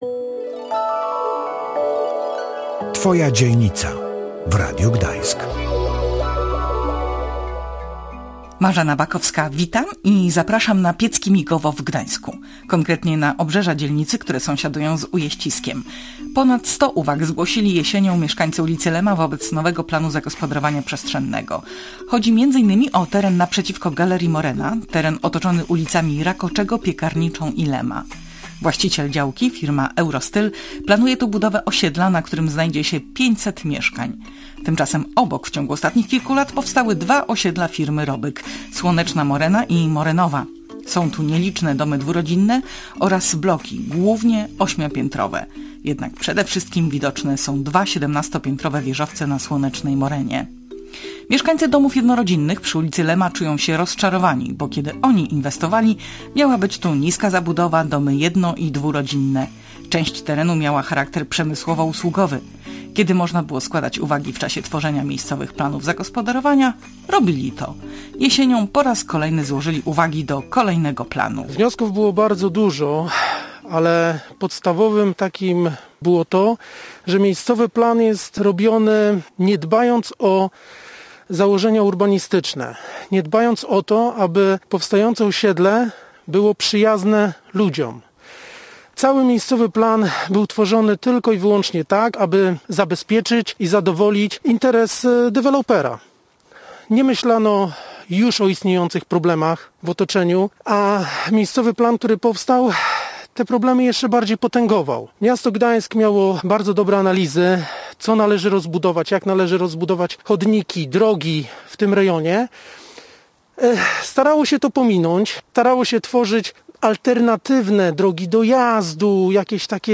mieszkańcy Słonecznej Moreny oraz składający uwagi do planów zagospodarowania przestrzennego mieszkańcy ulicy Lema.